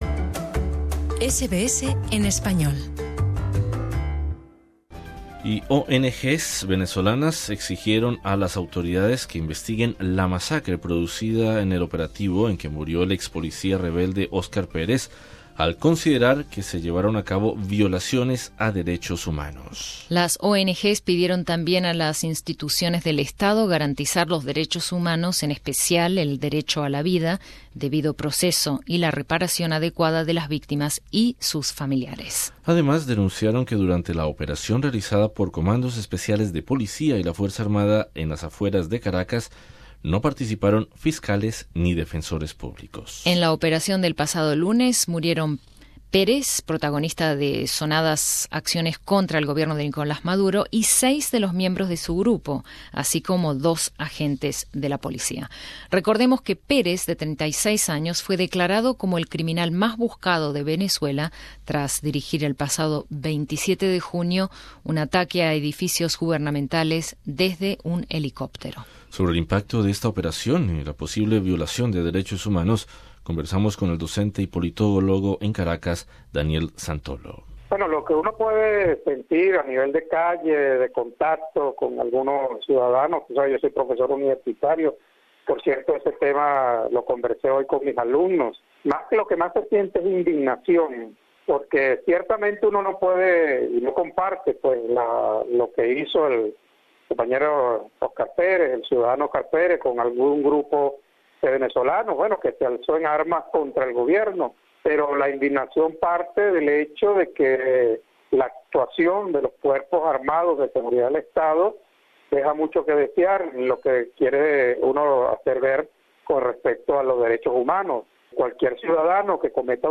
conversamos con el docente y politólogo en Caracas